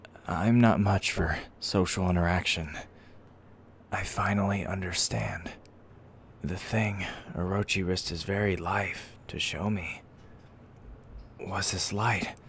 Voice Actor
Voice: Any pitch (preferably on the lower end), soft, possibly a bit quiet. Maybe also a bit kind